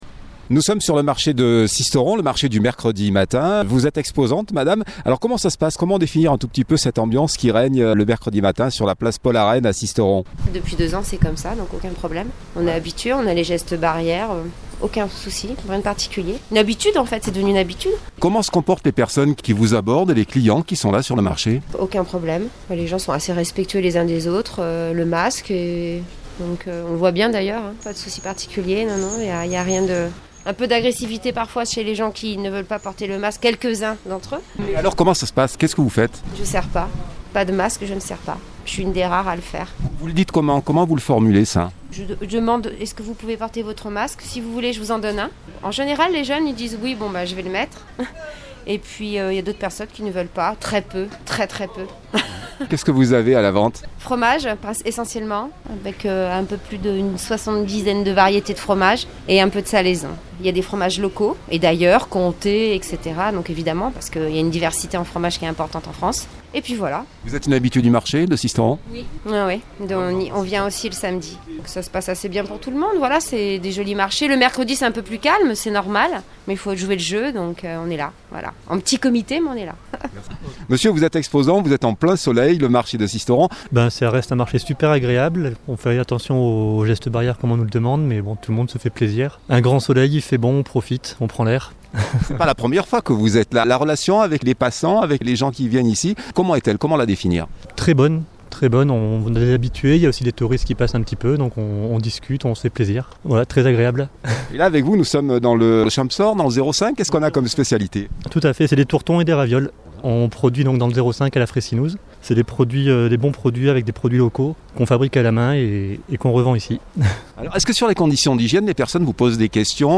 2022-01-06 - Sisteron-Marché du Mercredi.mp3 (5.6 Mo)